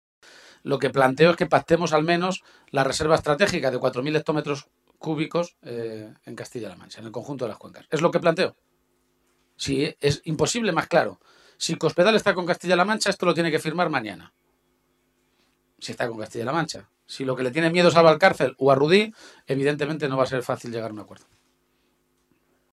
El nuevo secretario regional del PSOE de Castilla-La Mancha, Emiliano García-Page, ha comparecido esta tarde ante los medios de comunicación minutos antes de la reunión de la dirección regional socialista, que ha tenido lugar en Guadalajara.